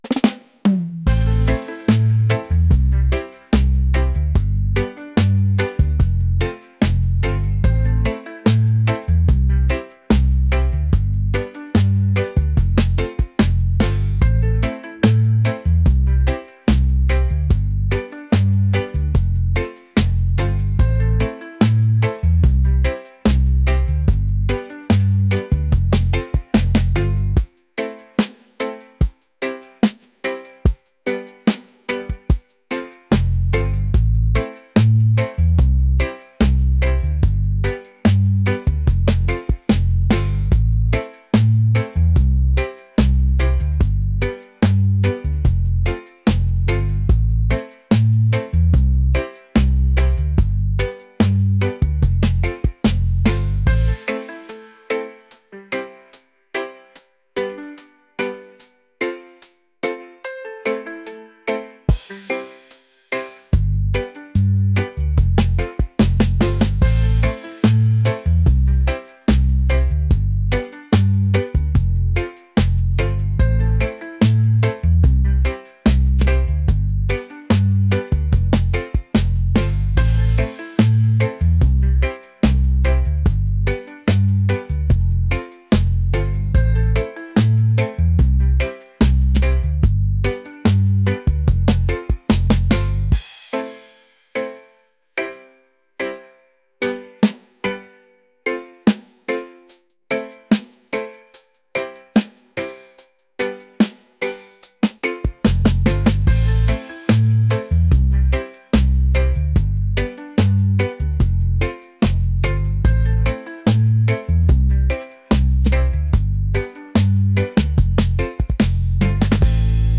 romantic | reggae | laid-back